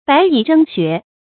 白蟻爭穴 注音： ㄅㄞˊ ㄧˇ ㄓㄥ ㄒㄩㄝˊ 讀音讀法： 意思解釋： 比喻競逐名利。